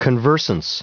Prononciation du mot conversance en anglais (fichier audio)
Prononciation du mot : conversance